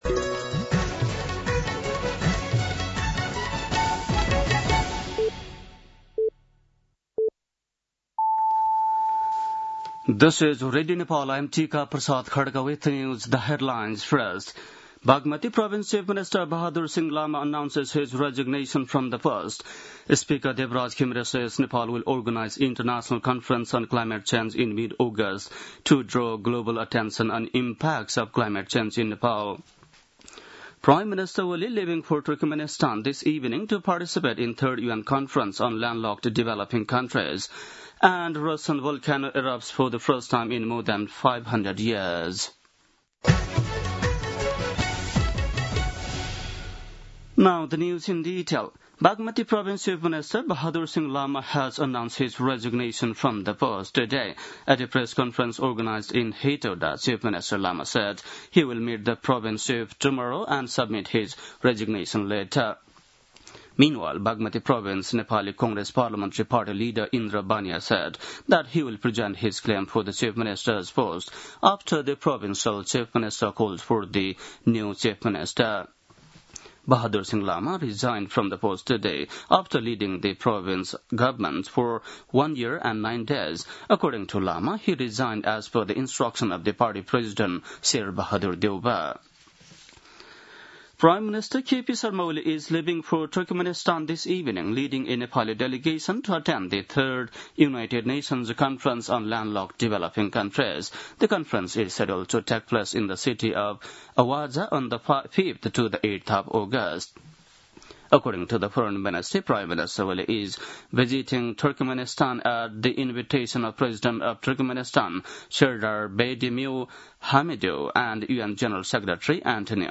बेलुकी ८ बजेको अङ्ग्रेजी समाचार : १८ साउन , २०८२
8.-pm-english-news-.mp3